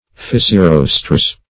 Search Result for " fissirostres" : The Collaborative International Dictionary of English v.0.48: Fissirostres \Fis`si*ros"tres\, n. pl.
fissirostres.mp3